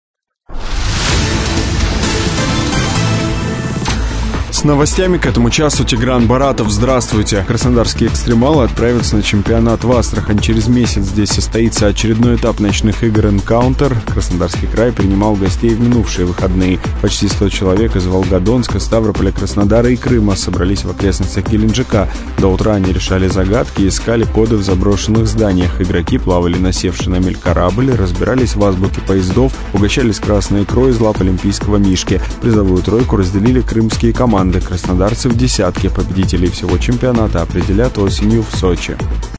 Воспользовалась служебным положением :) 10 минут назад новость в эфире Радио 107